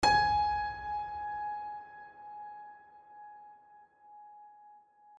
HardPiano
gs4.mp3